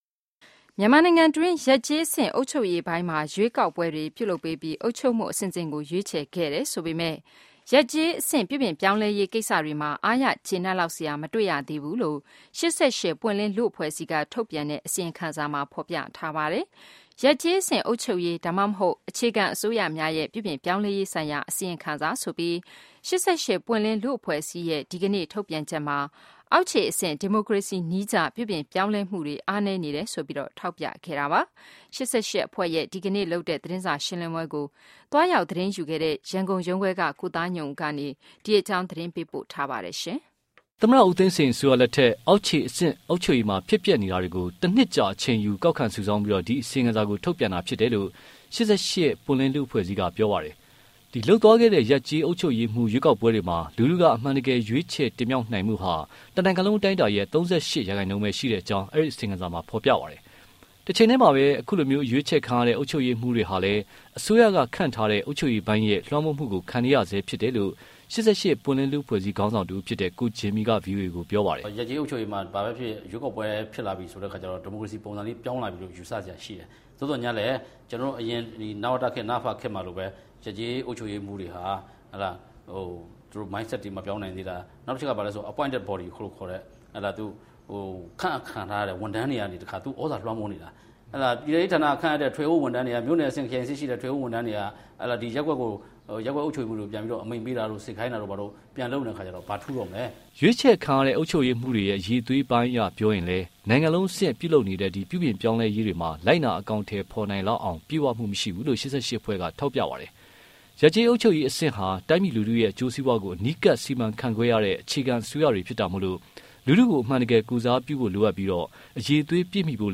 ၈၈ သတင်းစာရှင်းလင်းပွဲ